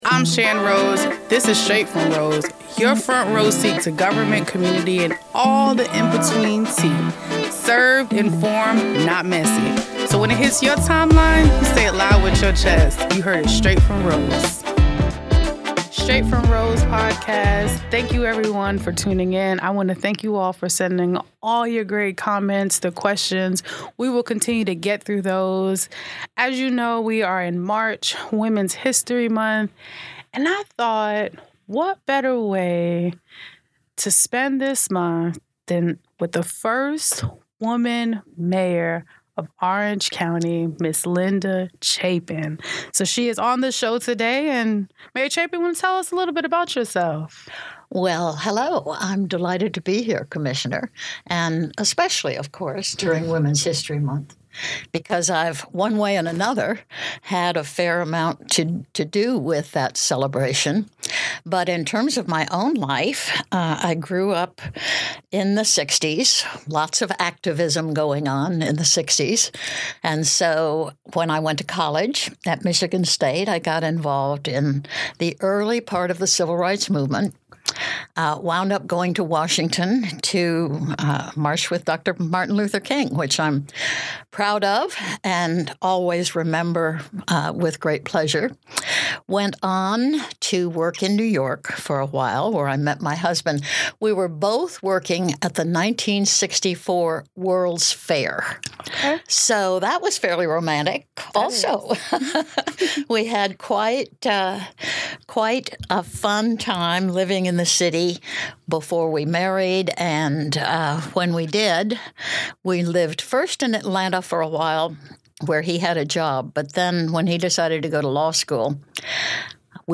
Interview with Linda Chapin the first major of Orange County